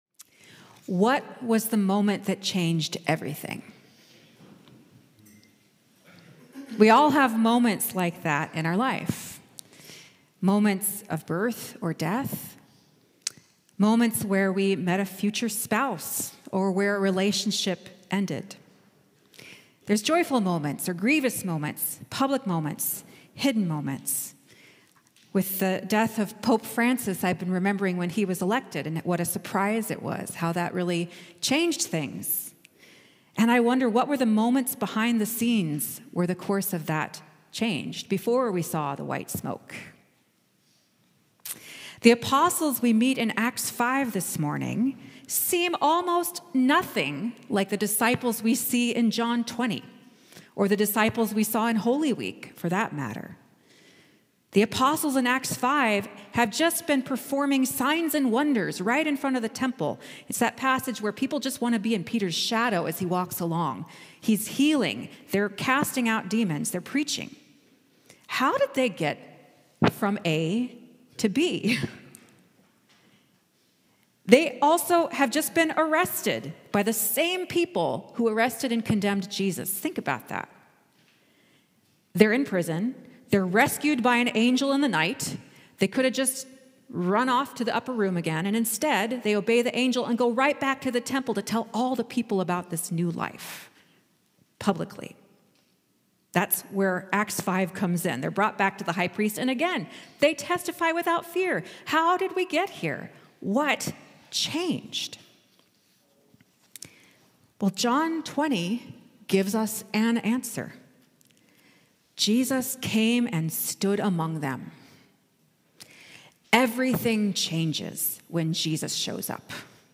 Sermon -